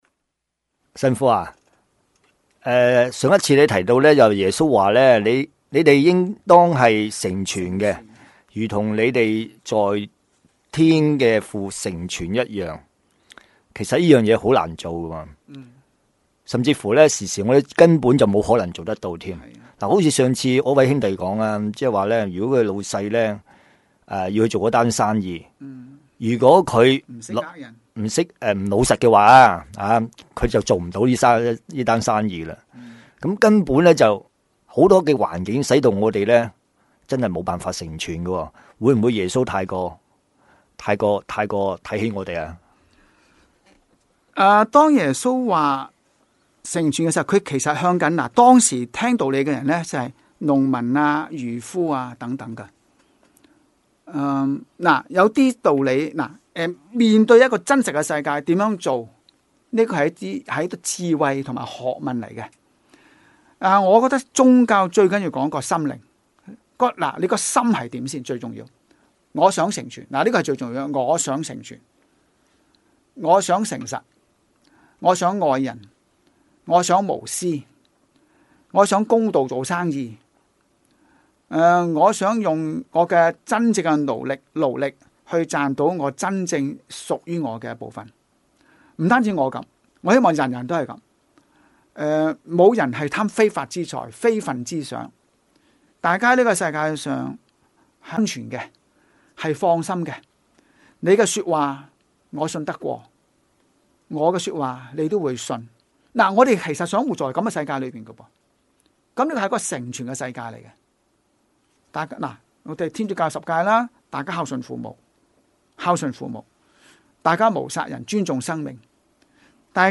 自2007年底，我們在新城財經台推出「金錢以外」電台廣播節目，邀請不同講者及團體每晚以五分鐘和我們分享金錢以外能令心靈快樂、生命富足的生活智慧，讓聽眾感悟天主的美善和睿智。